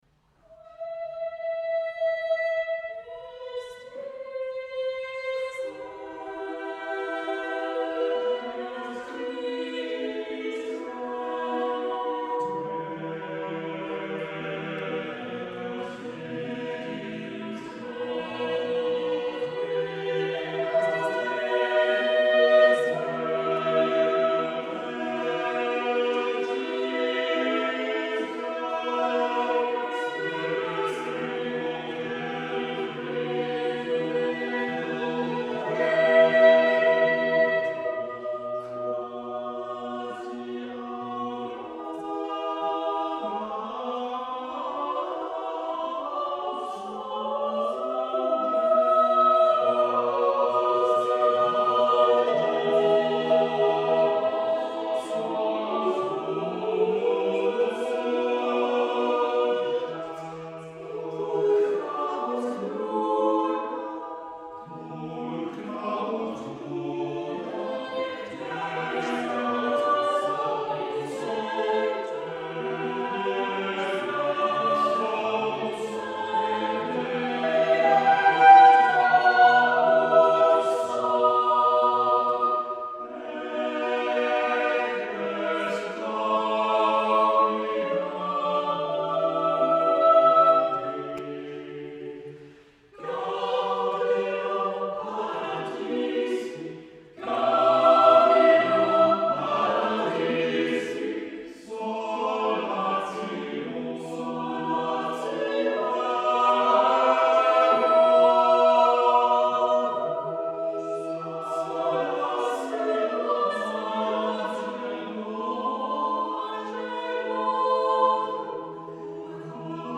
motet
five-part motets
the work received its modern-day world premiere by the Chalice Consort